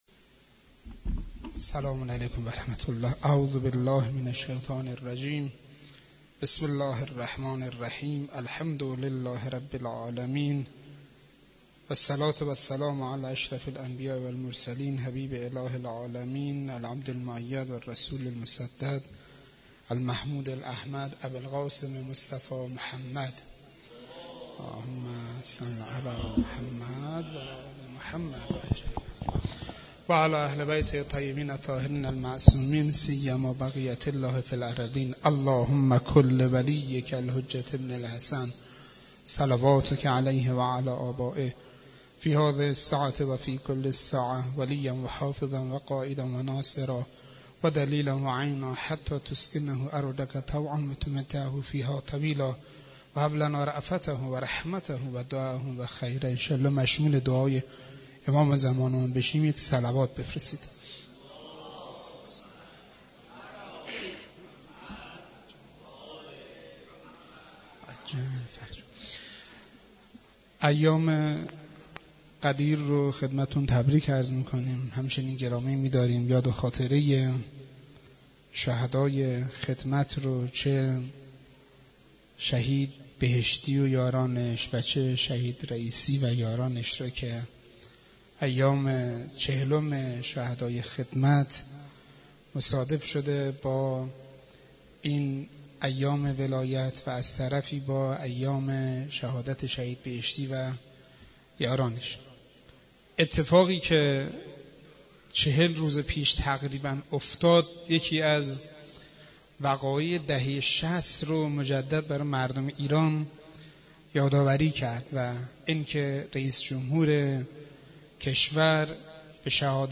در مسجد دانشگاه کاشان